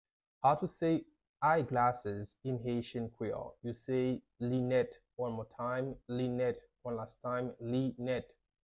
How to say Eyeglasses in Haitian Creole - Linèt pronunciation by a native Haitian Teacher
“Linèt” Pronunciation in Haitian Creole by a native Haitian can be heard in the audio here or in the video below:
How-to-say-Eyeglasses-in-Haitian-Creole-Linet-pronunciation-by-a-native-Haitian-Teacher.mp3